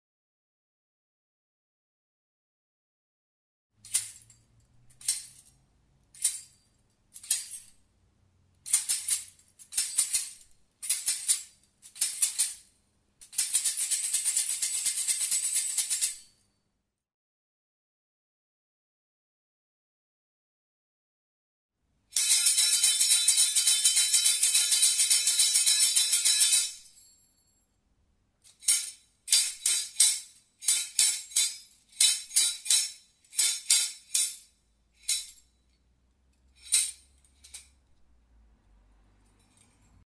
Traditional Egypt Instrument
Sistrum
Audio file of the Sistrum
Sound-of-Systrum.mp3